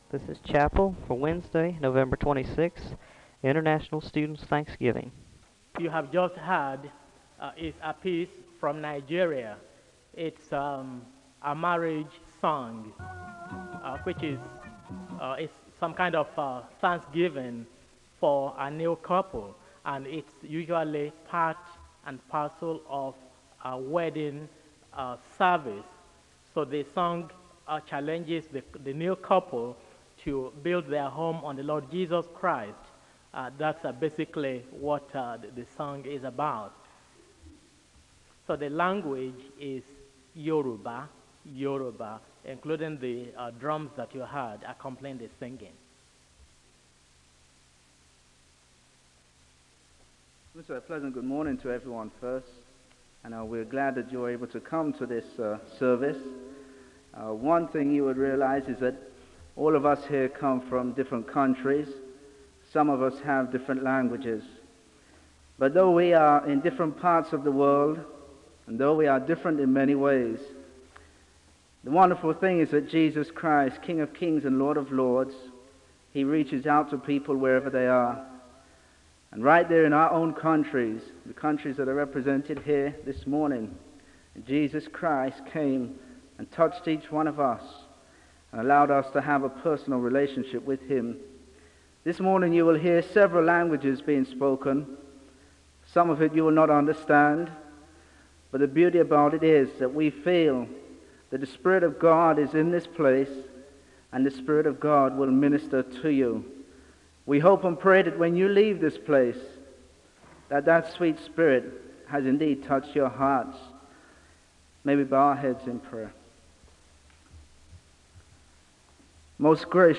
This chapel service is facilitated by international students at Southeastern Seminary.
There is a moment of prayer (1:59-3:22).
There is a song of worship (4:38-7:42). There is a meditation presenting in Japanese, then translated into English, speaking about the work of Jesus in one’s life (7:43-18:01).